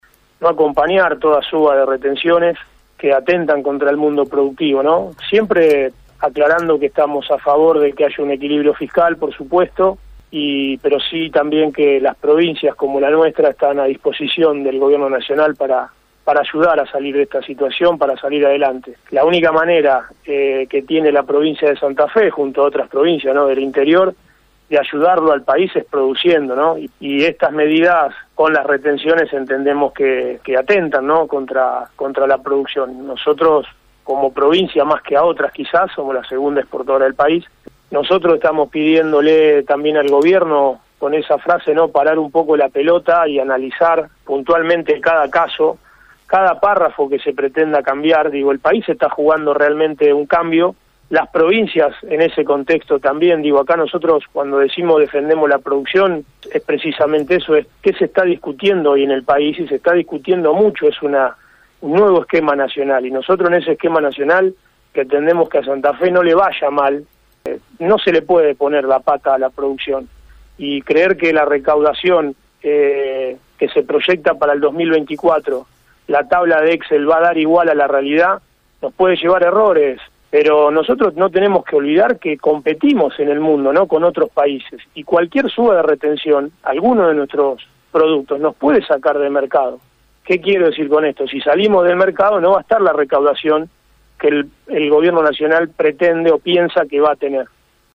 Escucha la palabra de Gustavo Puccini en Radio EME:
MINISTRO-DESARROLLO-PRODUCTIVO-GUSTAVO-PUCCINI-SOBRE-RETENCIONES.mp3